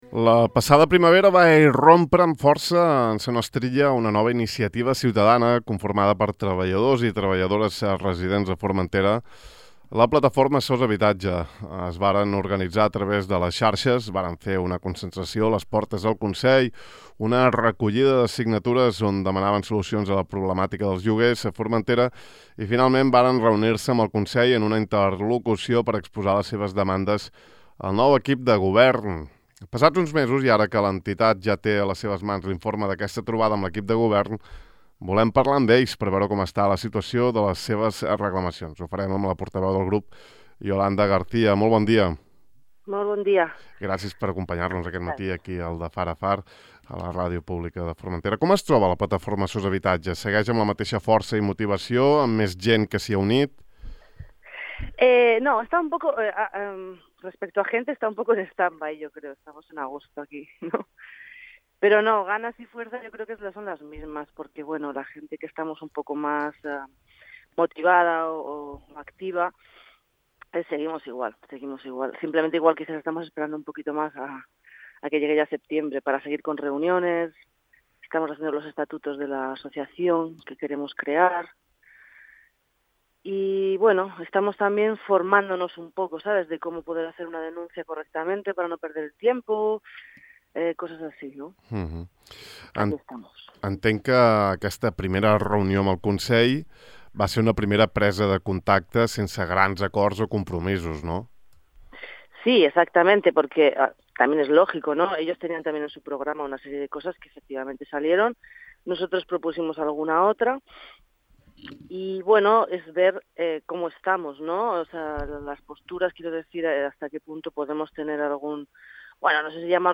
Podeu escoltar íntegrament l’entrevista d’avui al De Far a Far